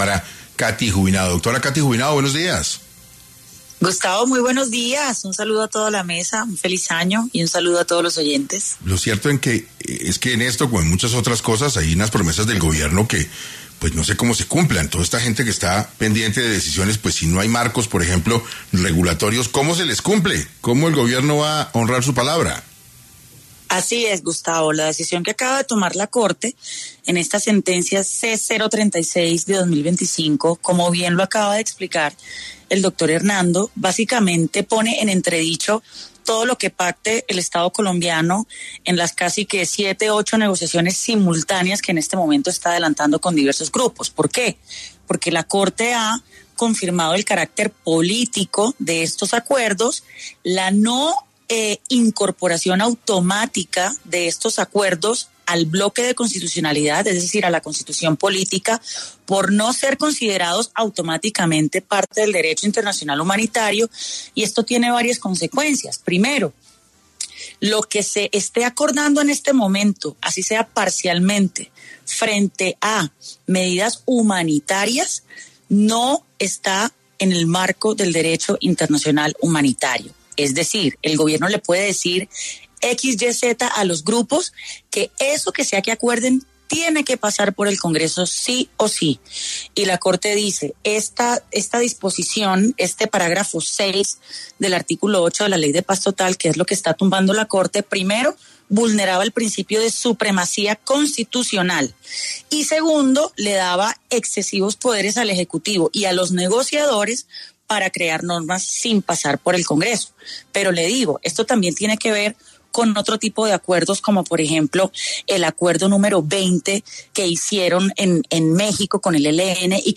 En entrevista para 6AM, Cathy Juvinado, representante a la cámara, explicó cuáles son los principios jurídicos de esta decisión y cómo funciona.